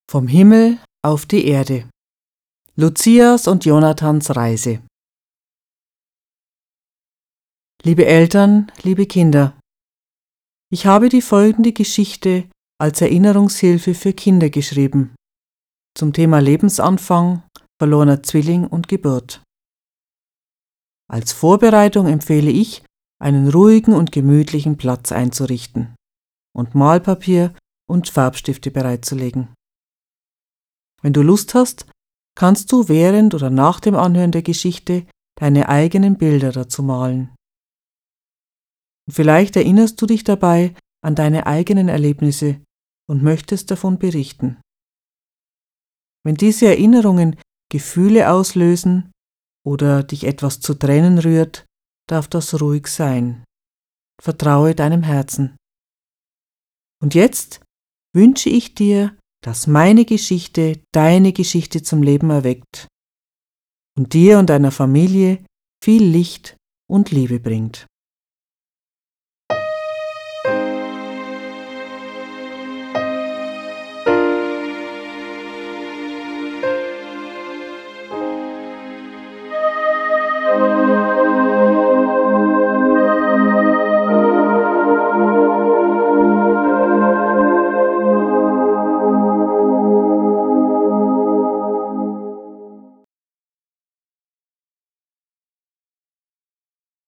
Hörbuch mit Musik Doppel-CD:  CD1 Hochdeutsch   CD2 Schweizerdeutsch